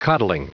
Prononciation du mot coddling en anglais (fichier audio)
Prononciation du mot : coddling